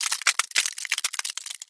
Звуки разбитого сердца